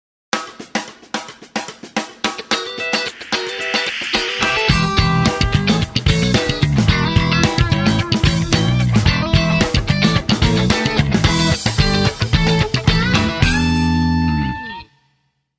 Funk Rhythm Part
Funk Lead Part